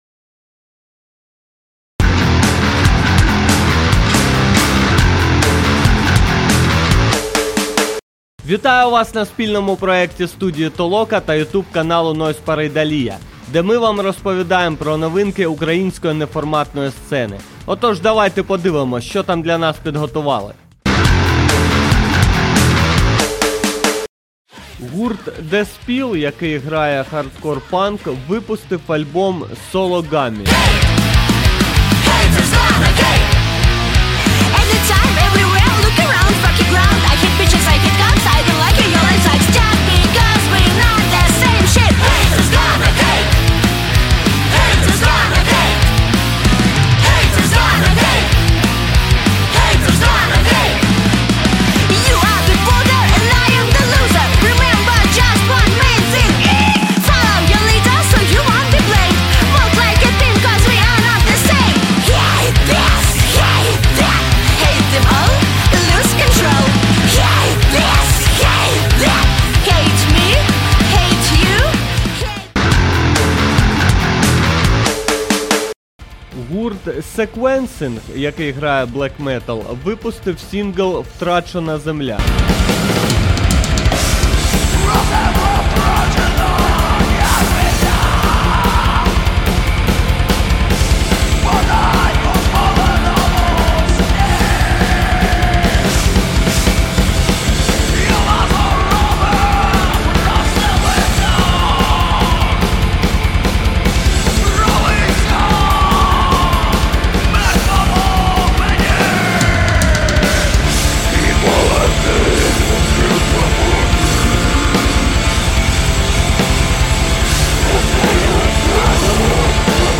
Стиль: Подкаст